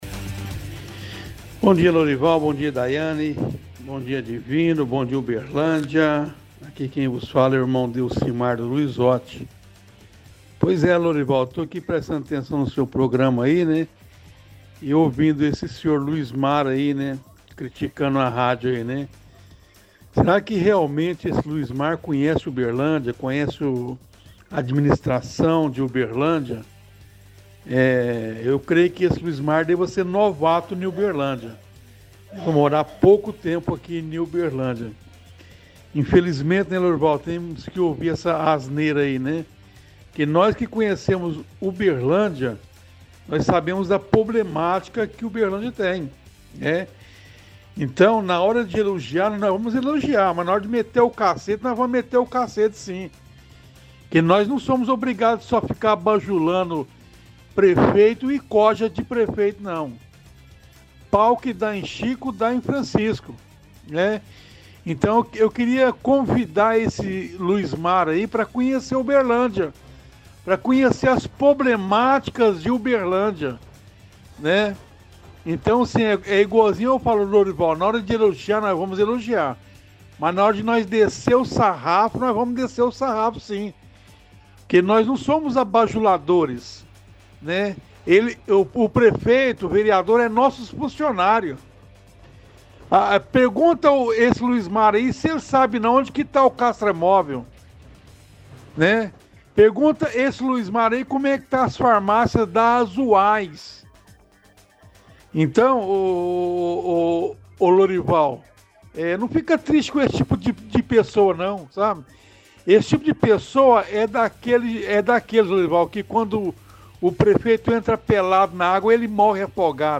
– Ouvinte critica outro ouvinte que criticou a rádio. Reclama de falta de remédios nas farmácias e obras da prefeitura.